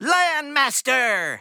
File:Fox voice sample SSB4 EN.oga
Fox_voice_sample_SSB4_EN.oga.mp3